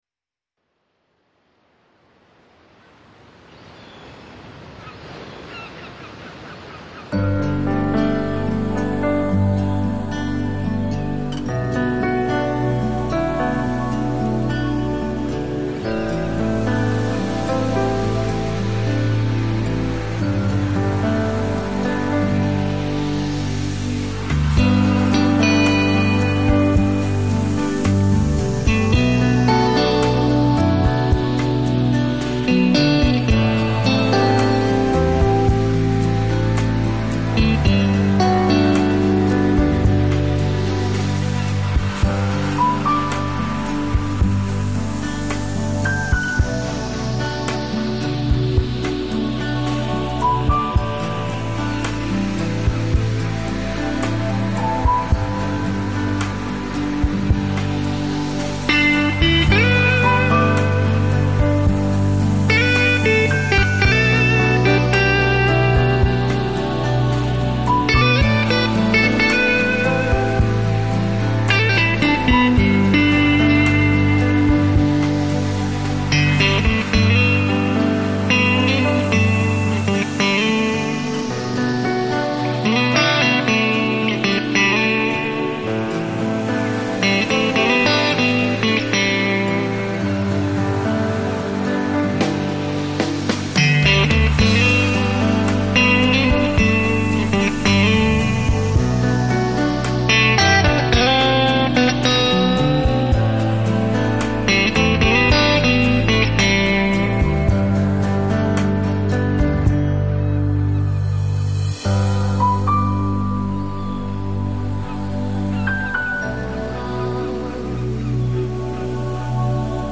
Lounge, Chill Out, Downtempo, Guitar